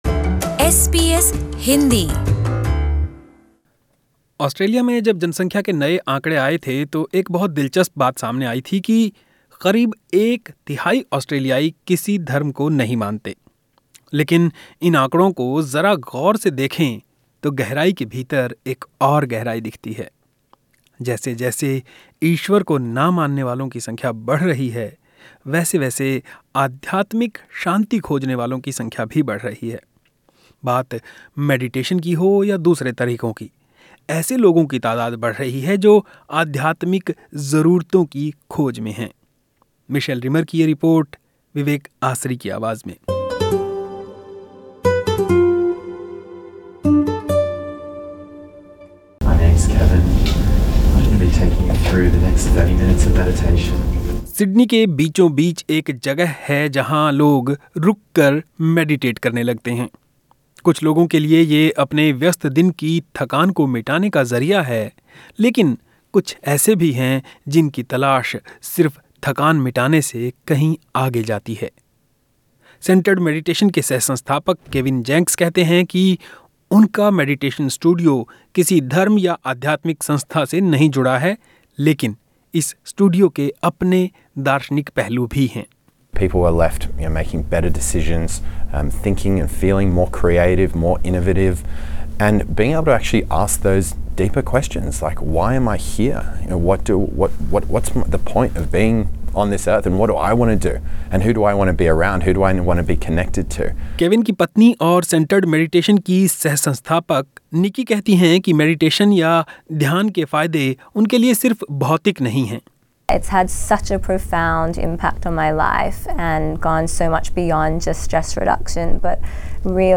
ये रिपोर्ट